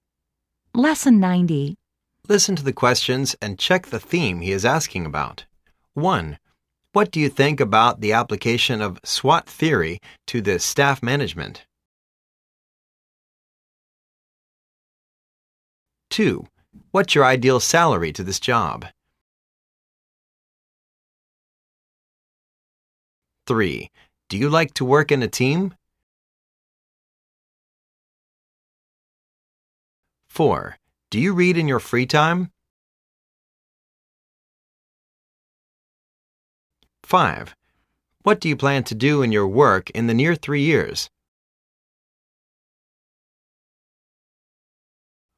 Listen to the questions and check the theme he is asking about.